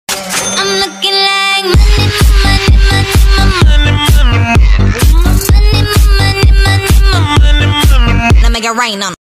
youtube-twitch-_-alert-sound-effect-_-donation-1_Q178xhJ.mp3